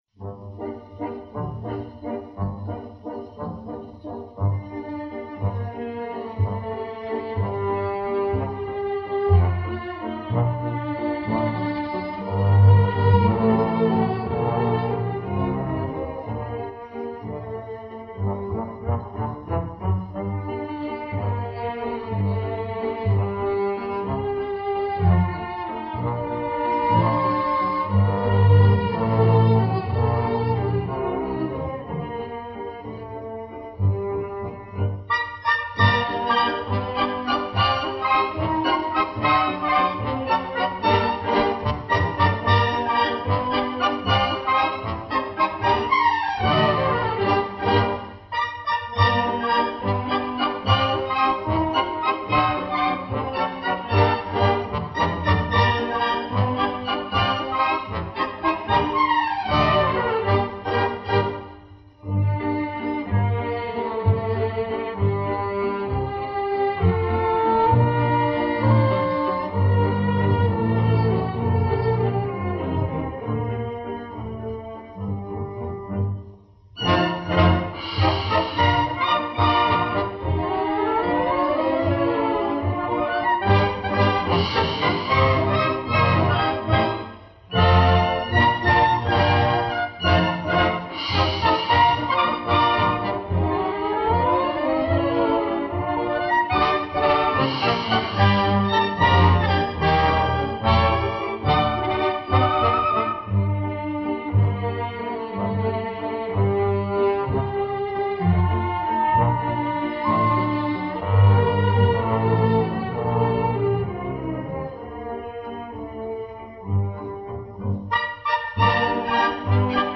Запись с патефонной пластинки.